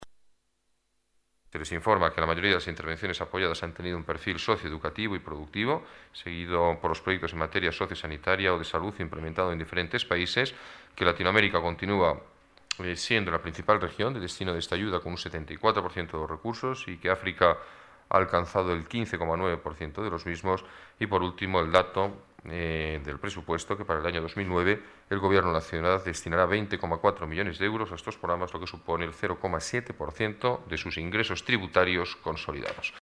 Nueva ventana:Declaraciones alcalde